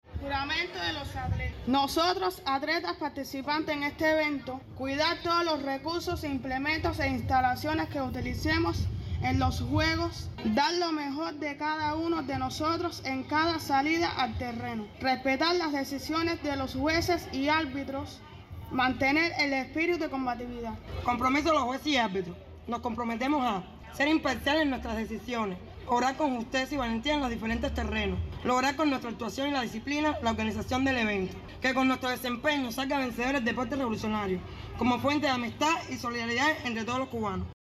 PEDRO BETANCOURT.- Como parte de las actividades en saludo al Día Internacional de las Personas con Discapacidad, el Consejo Voluntario Deportivo Jaime López Snecada, de este municipio, devino sede para la realización de los XIX Juegos Locales de las Olimpiadas Especiales.